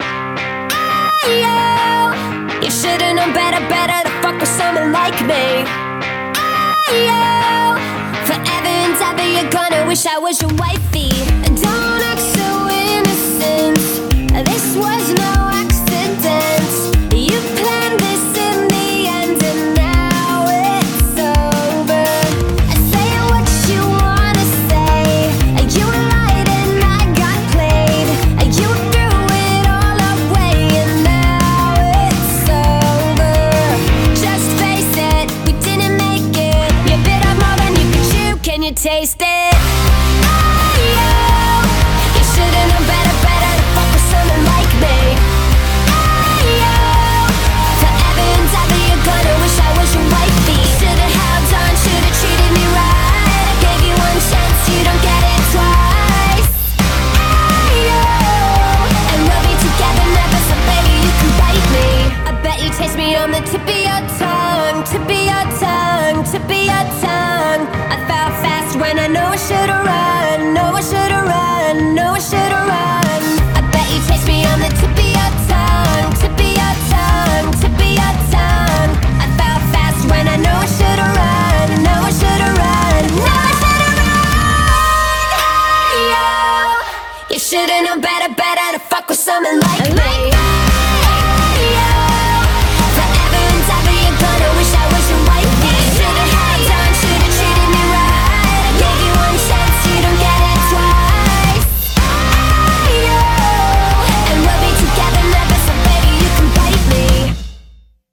BPM170
Audio QualityMusic Cut